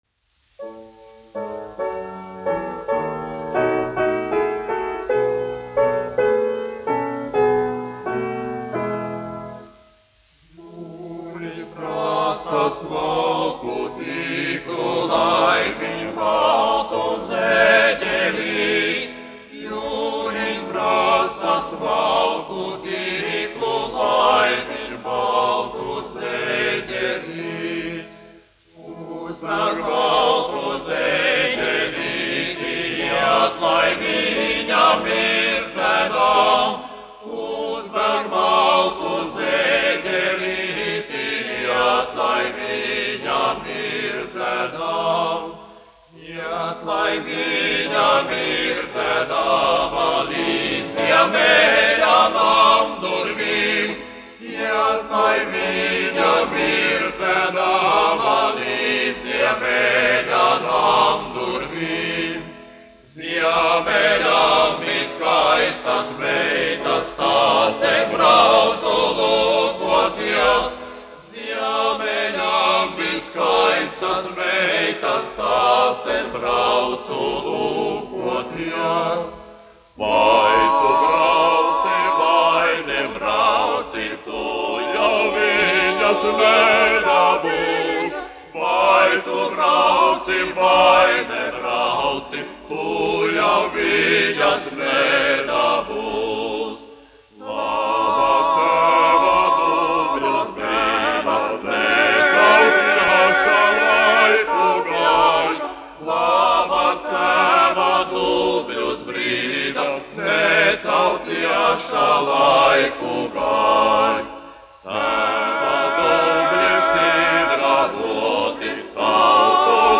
Latvijas Filharmonijas vīru vokālais kvartets, izpildītājs
1 skpl. : analogs, 78 apgr/min, mono ; 25 cm
Latviešu tautasdziesmas
Vokālie kvarteti
Skaņuplate